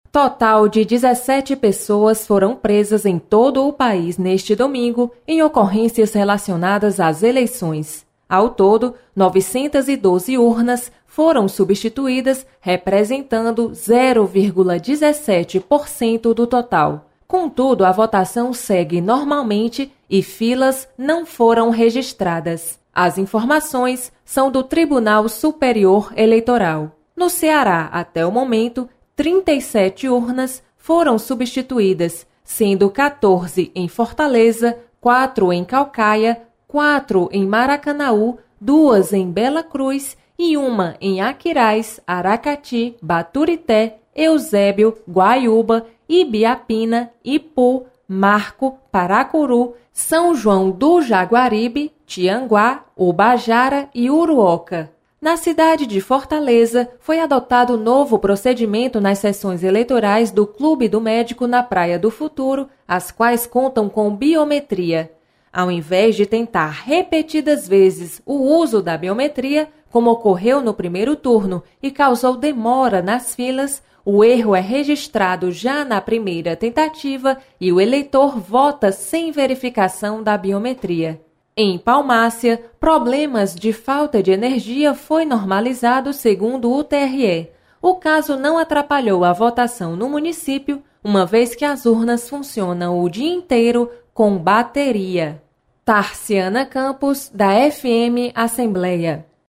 Você está aqui: Início Comunicação Rádio FM Assembleia Notícias Eleições 2018